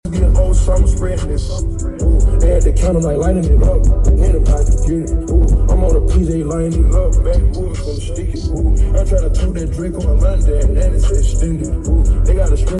2023 Cadillac Escalade dos 12 en sonido AkG algo regular